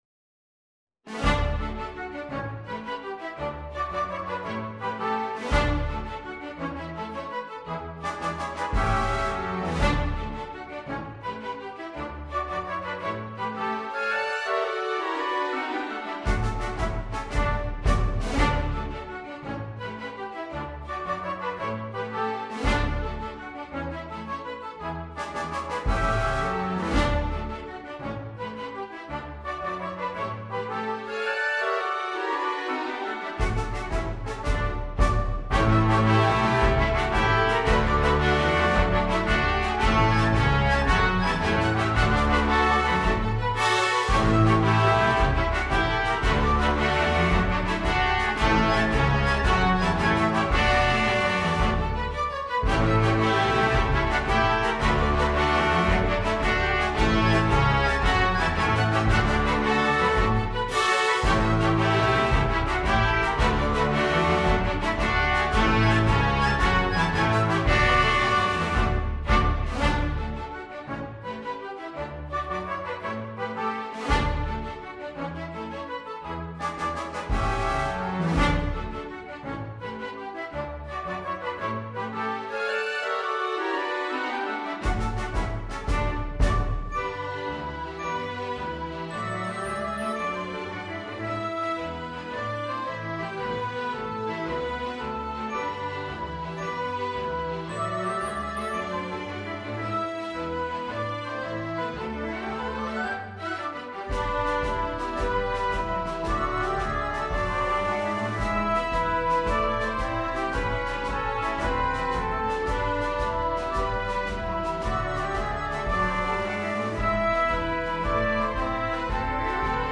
Title Soap Bubbles Subtitle Characteristic March Dedication Composer Allen, Thomas S. Arranger Date 1904 Style March Instrumentation Salon Orchestra Score/Parts Download Audio File:Soap Bubbles.mp3 Notes